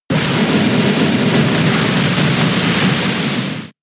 Water Spout
water-spout.mp3